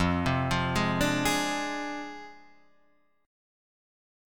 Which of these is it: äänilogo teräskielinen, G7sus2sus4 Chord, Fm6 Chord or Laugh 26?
Fm6 Chord